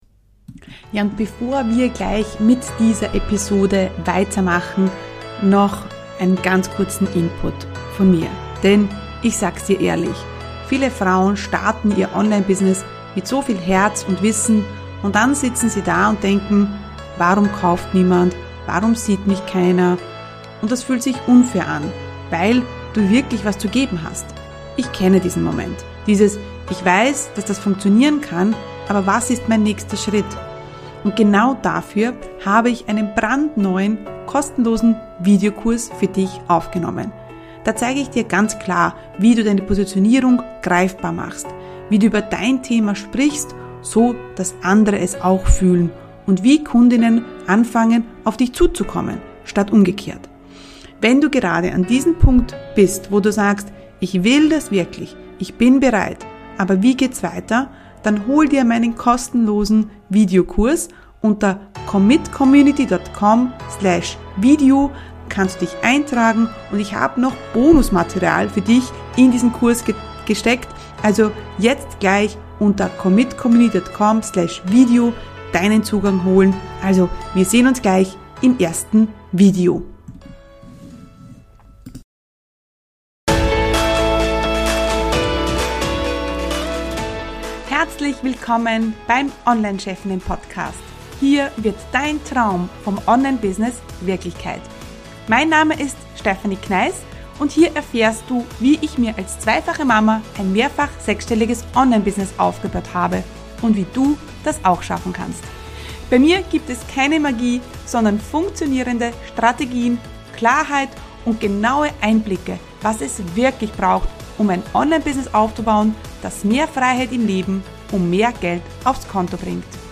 Interview ~ Online Chefinnen Podcast